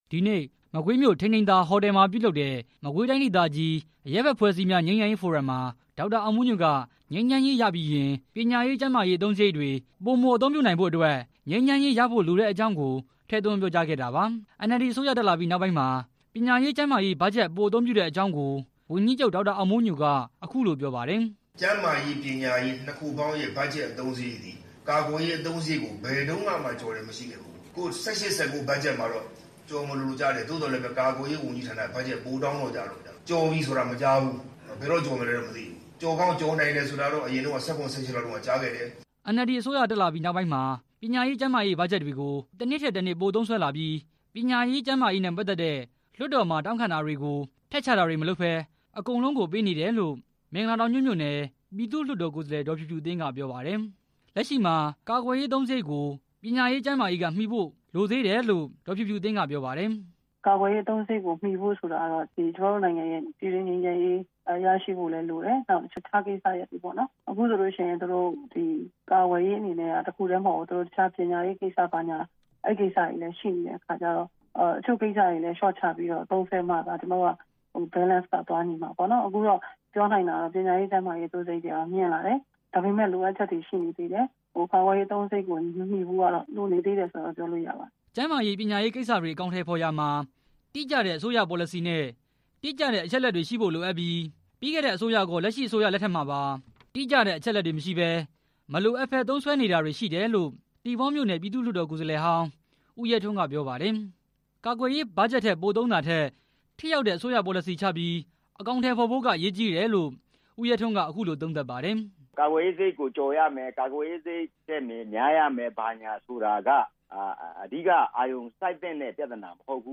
ဒီနေ့ မကွေးမြို့ ထိန်ထိန်သာ ဟိုတယ်မှာပြုလုပ်တဲ့ မကွေးတိုင်းဒေသကြီး “အရပ်ဘက်အဖွဲ့အစည်းများ ငြိမ်းချမ်းရေးဖိုရမ်”  မှာ ဒေါက်တာ အောင်မိုးညိုက ပညာရေး ကျန်းမာရေး အသုံးစရိတ်ဟာ ကာကွယ်ရေး အသုံးစရိတ်ကို ဘယ်တုန်းကမှ ကျော်တယ်လို့ မရှိခဲ့ကြောင်း၊ ဒီ ၂၀၁၈၊ ၂၀၁၉ ခုနှစ် ဘတ်ဂျက်မှာ အသုံးစရိတ် ကျော်မလိုဖြစ်ခဲ့ပေမယ့် ကာကွယ်ရေးဝန်ကြီးဌာနက  ဘတ်ဂျက်တိုးတောင်းခဲ့ကြောင်းနဲ့ နောက်ပိုင်းမှာတော့ ကျော်နိုင်တယ်လို့ ကြားသိရကြောင်း ပြောပါတယ်။